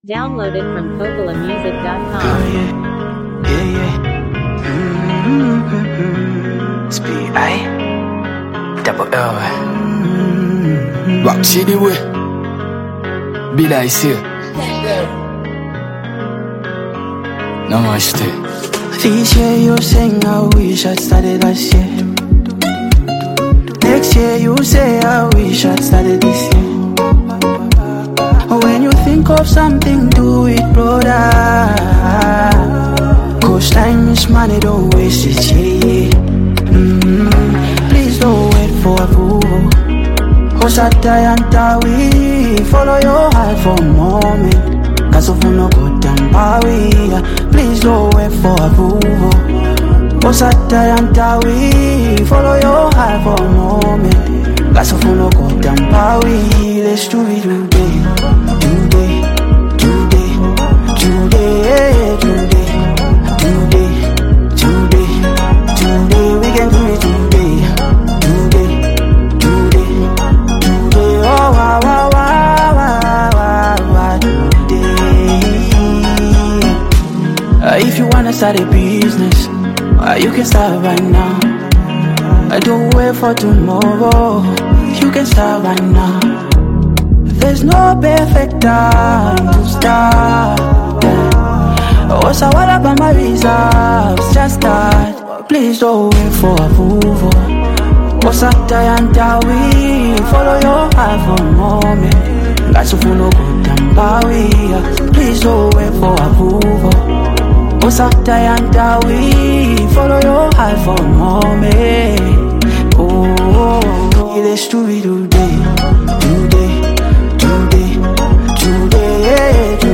featuring rapper
contemporary African sound